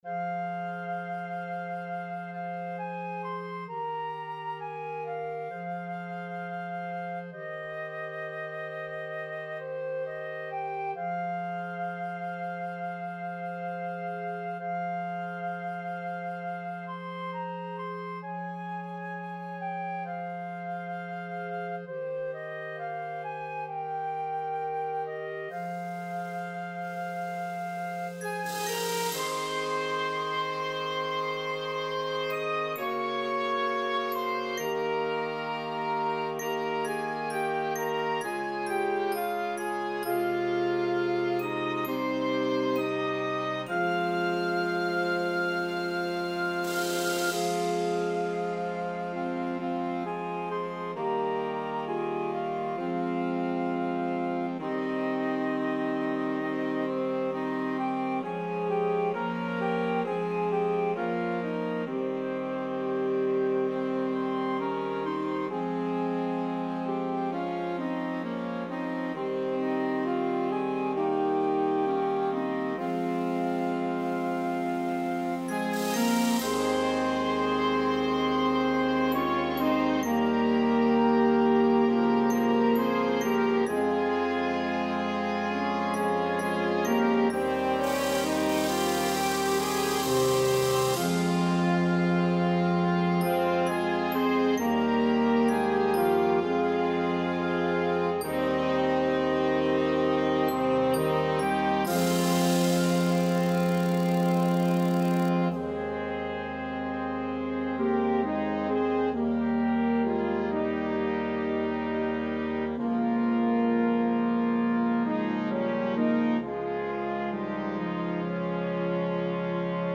A tuneful melody will stick in the players head.